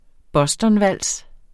Udtale [ ˈbʌsdʌn- ]